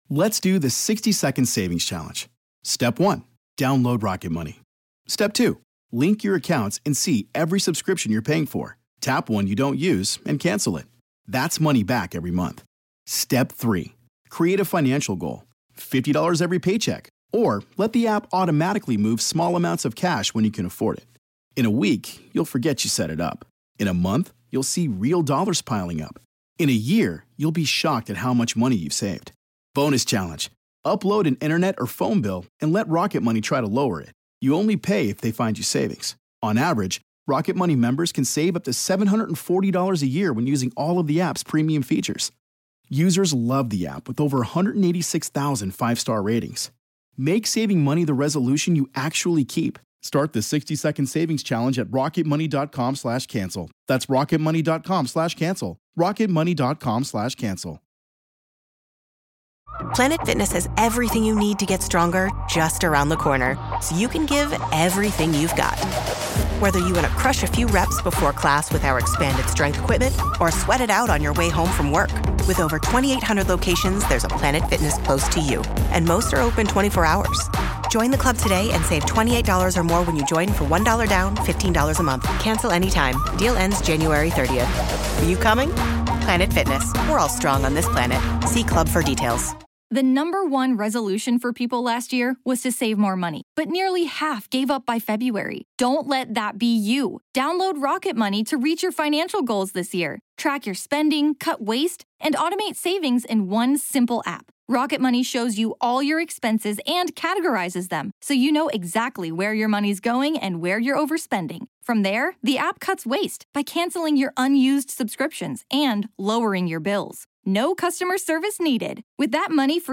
Today, in Part One of our conversation, we hear about some of those journeys. Journeys that would lead her to confront disturbed souls who once tormented the living in their lifetimes and some who never walked the earth.